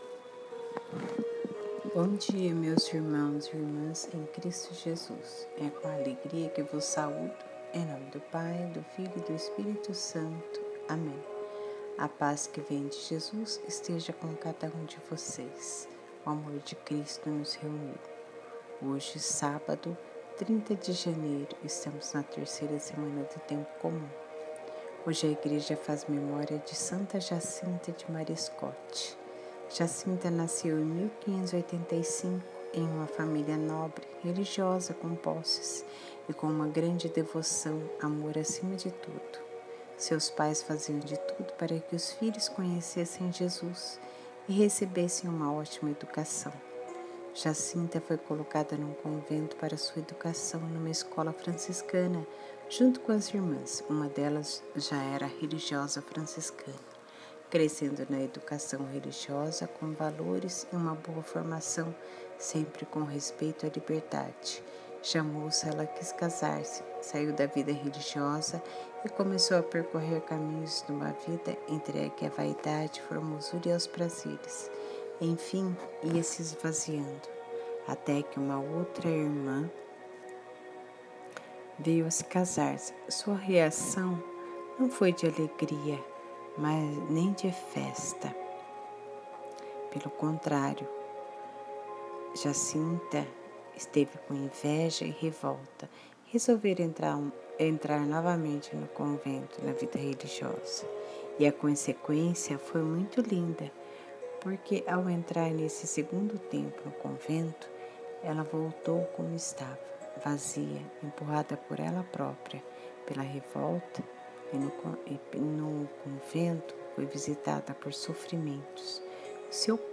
EVANGELHO DE HOJE